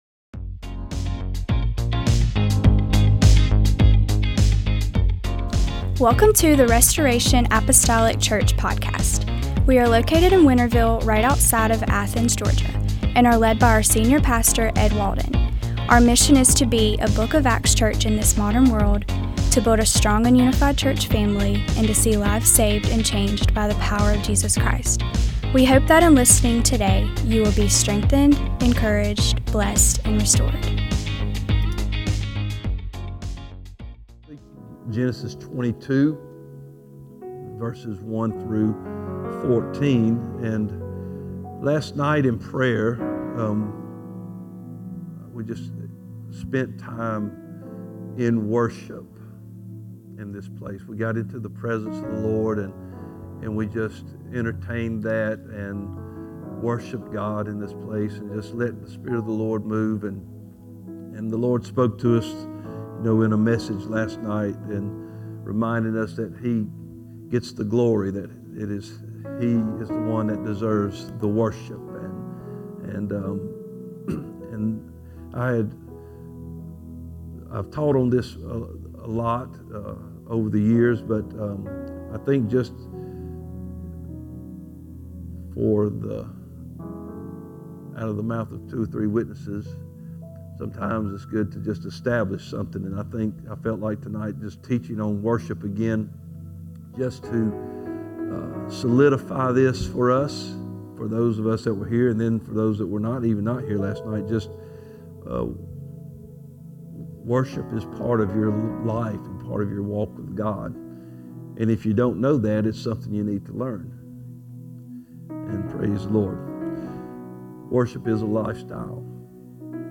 MDWK Bible Study - 12/03/2025 -